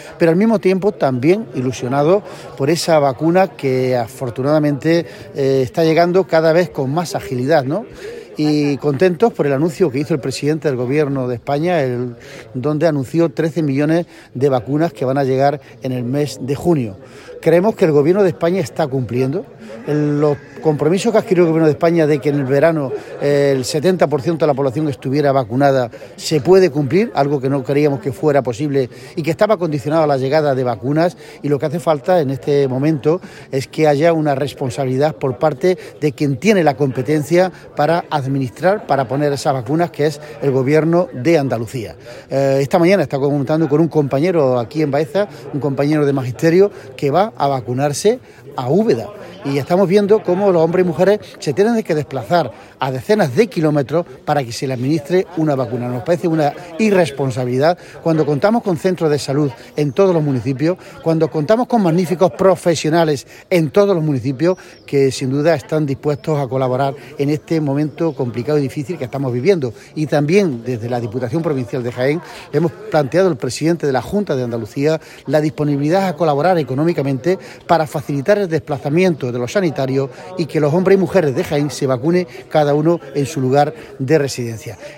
Jornada de trabajo en Baeza
Cortes de sonido